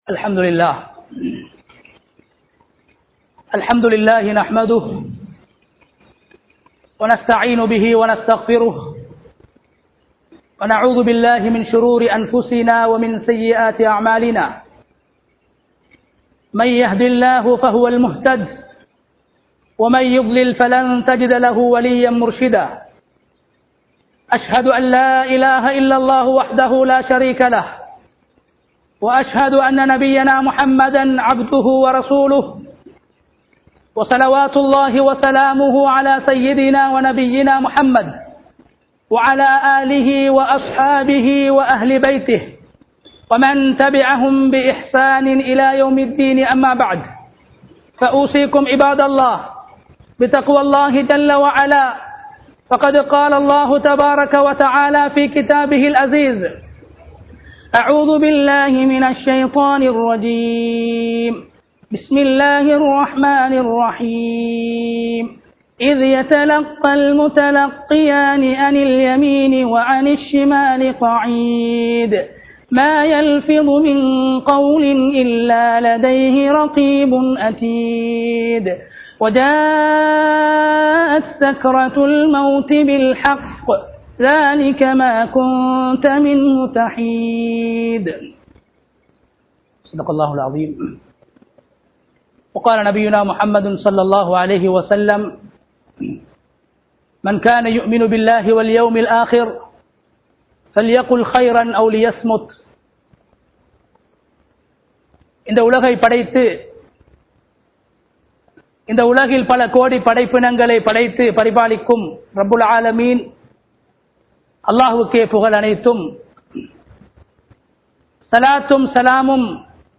நாவைப் பேணுவோம் | Audio Bayans | All Ceylon Muslim Youth Community | Addalaichenai
Wahangoha Jumua masjith 2021-07-23 Tamil Download